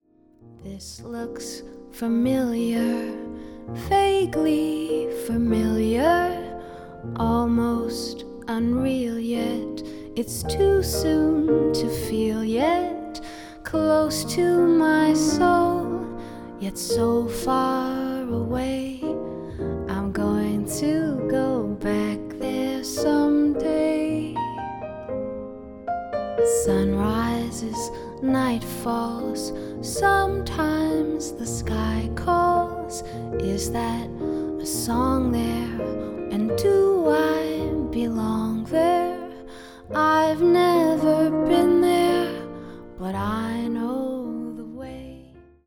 vocal
bass, piano, vibraphones
gutiar
cello